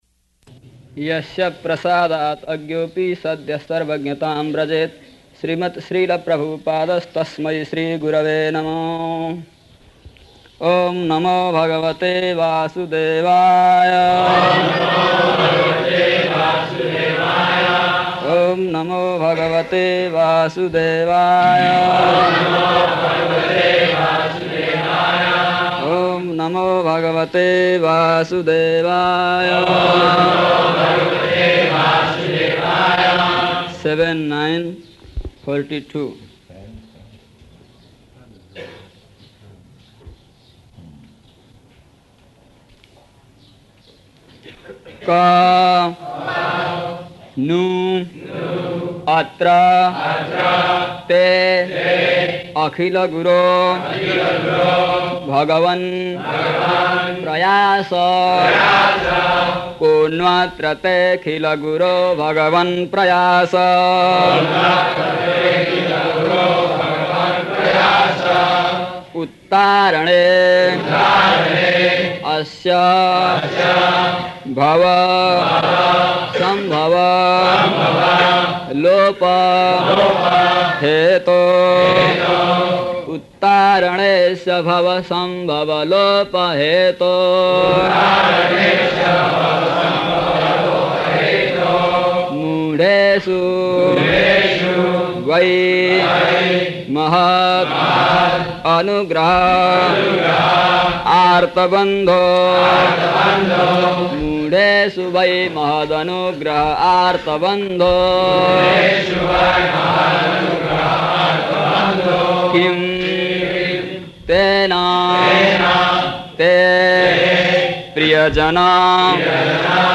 March 22nd 1976 Location: Māyāpur Audio file
[Prabhupāda and devotees repeat]
[chants verse; Prabhupāda and devotees respond]